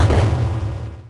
Techmino/media/effect/chiptune/clear_4.ogg at 34ac5eed9377a20aa43430bec3d50008d74e2ebf
clear_4.ogg